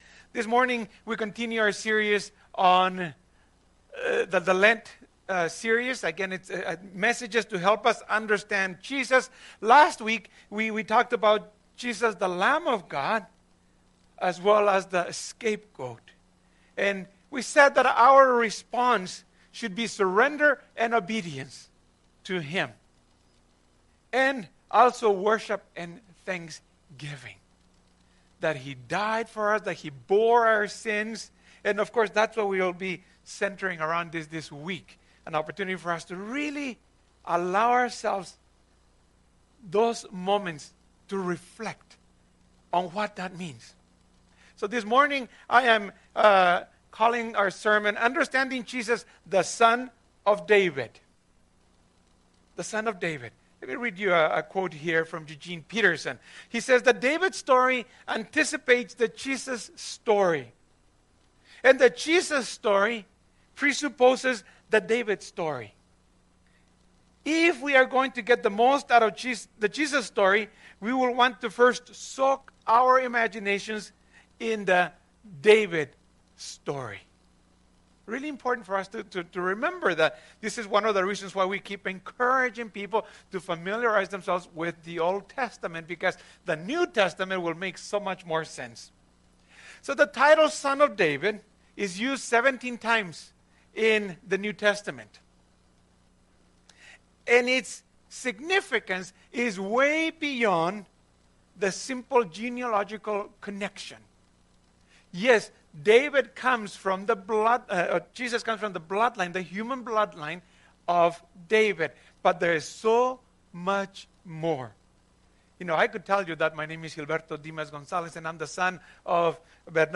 Sermons | Devon Community Church
This is the fourth sermon in the 2021 Lent Series which is designed to help us better understand who Jesus is.